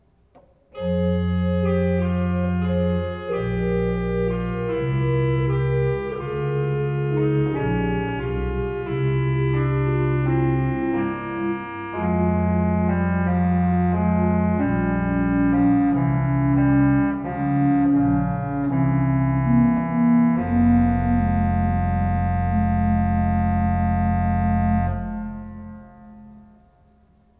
"An die Pfeifen, fertig, los!" Minierzählkonzert Kinderorgeltag am 09. August
Orgelthema 2